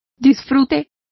Complete with pronunciation of the translation of enjoyment.